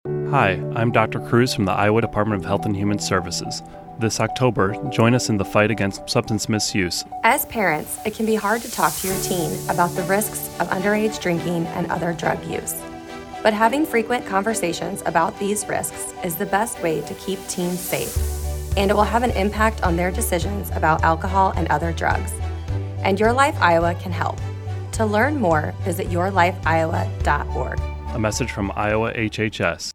:30 Radio Spot | Substance Misuse (Teen)